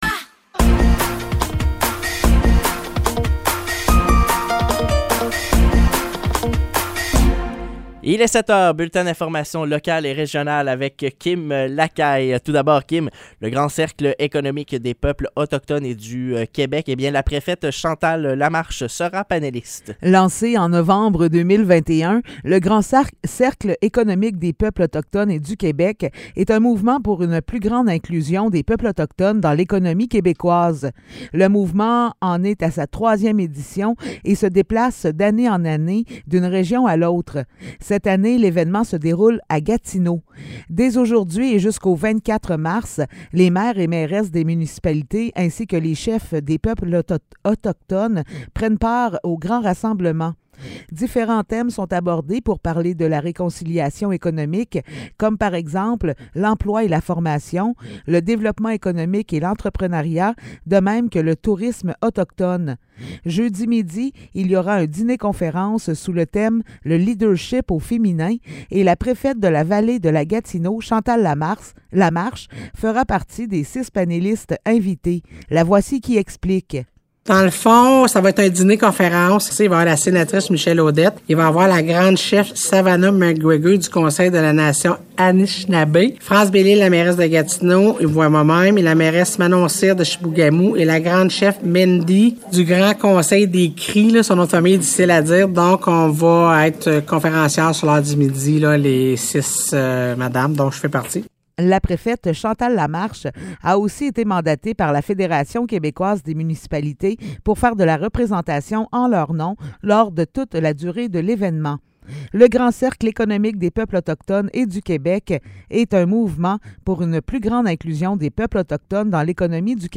Nouvelles locales - 21 mars 2023 - 7 h